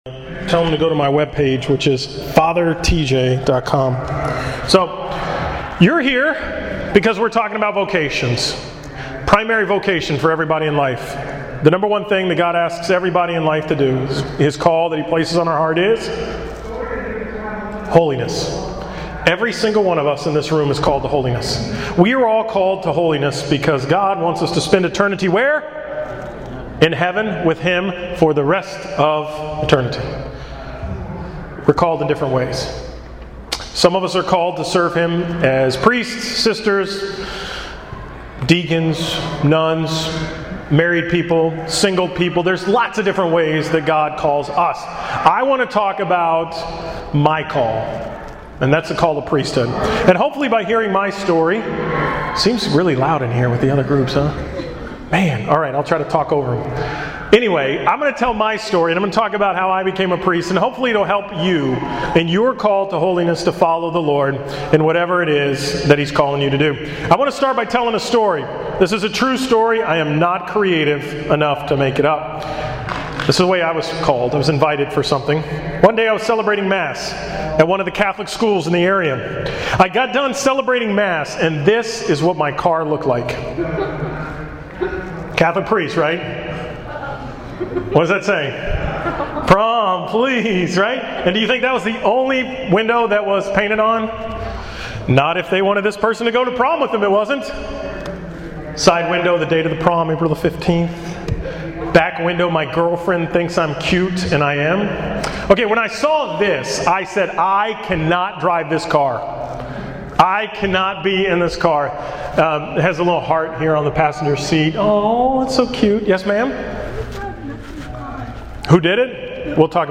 This is the talk I gave at one of the breakout sessions at AYC: https